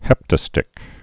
(hĕptə-stĭk)